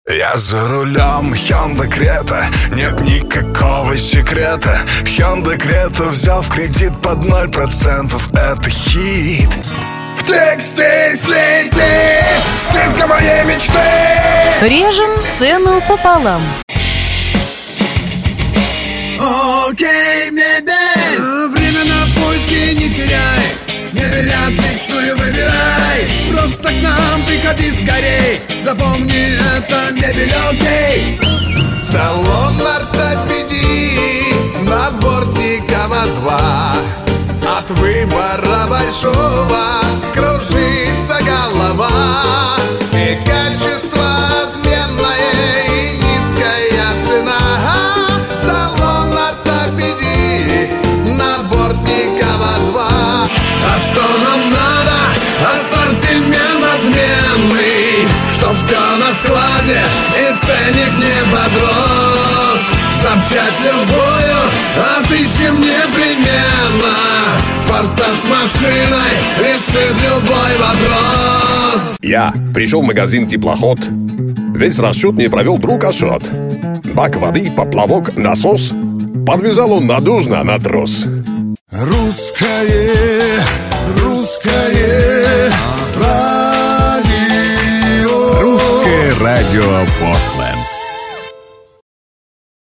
Муж, Вокал/Средний
Звуковая карта leyla echo, перамп dbx 376, микрофон NEUMANN TLM 103, акустическая кабина